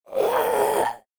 DayZ-Epoch/SQF/dayz_sfx/zombie/idle_25.ogg at 07c1105dcde01b783f7a842e38a16ef91cf33df1